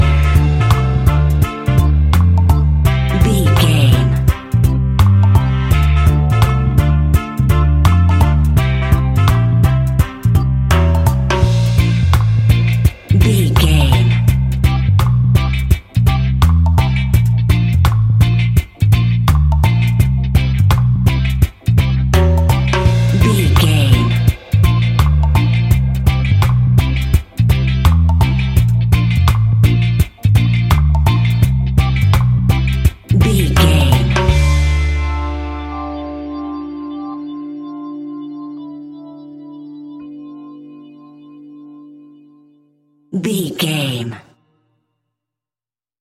Classic reggae music with that skank bounce reggae feeling.
Aeolian/Minor
D
instrumentals
reggae music
laid back
chilled
off beat
drums
skank guitar
hammond organ
transistor guitar
percussion
horns